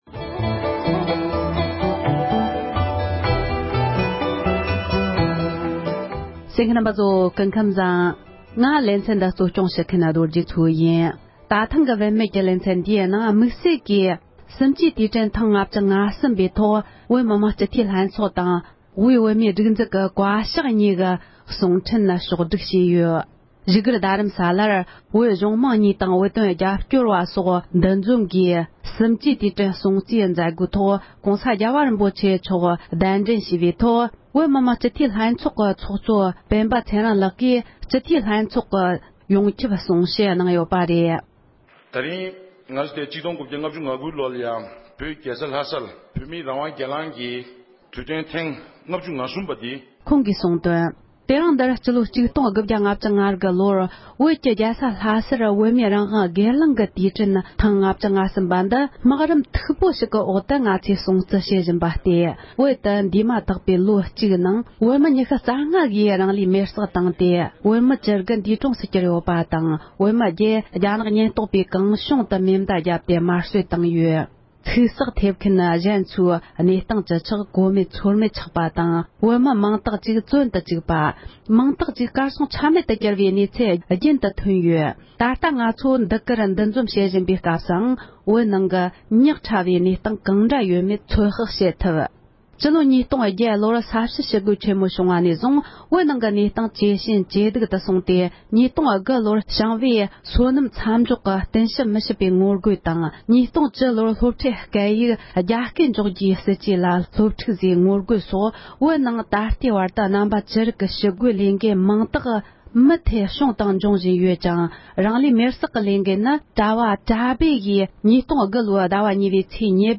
གསུམ་བཅུ་དུས་དྲན་ཐེངས་༥༣པའི་ཐོག་བཀའ་ཤག་དང་བོད་མི་མང་སྤྱི་འཐུས་ལྷན་ཚོགས་གཉིས་ནས་བསྩལ་བའི་གསུང་བཤད།